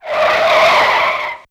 CarScreech1.wav